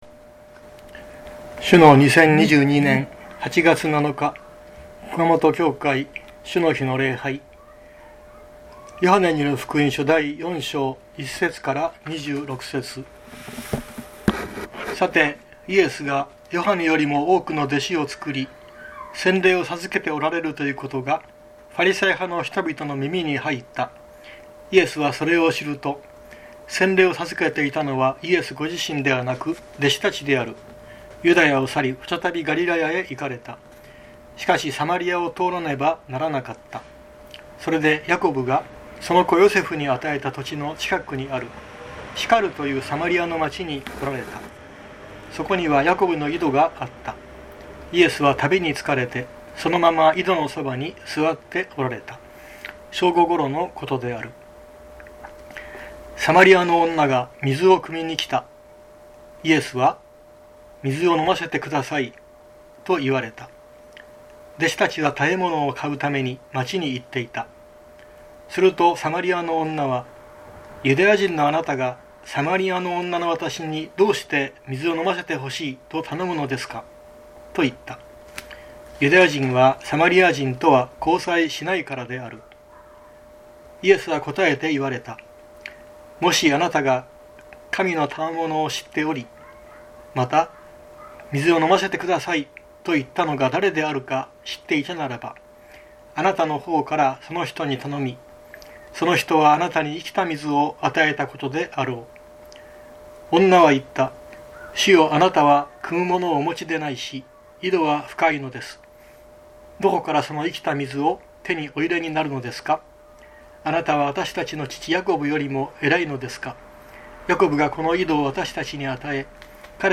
熊本教会。説教アーカイブ。